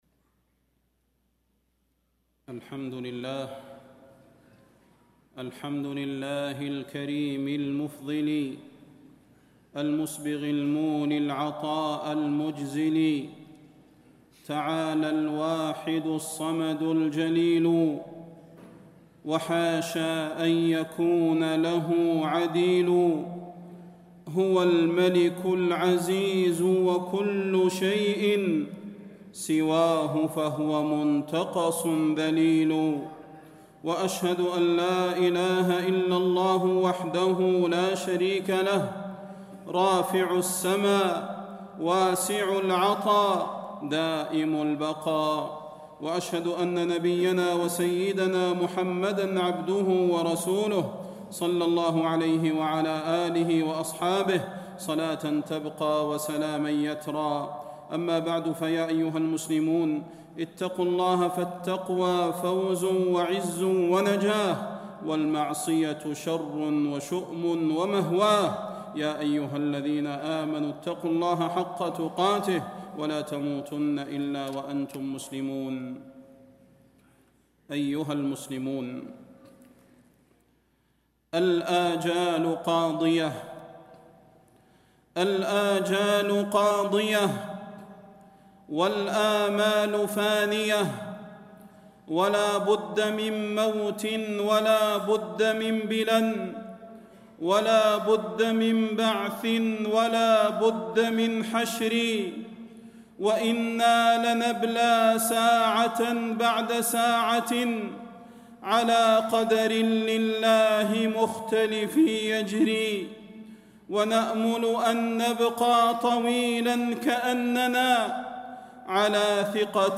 فضيلة الشيخ د. صلاح بن محمد البدير
تاريخ النشر ٣٠ شوال ١٤٣٤ هـ المكان: المسجد النبوي الشيخ: فضيلة الشيخ د. صلاح بن محمد البدير فضيلة الشيخ د. صلاح بن محمد البدير الاستعداد للموت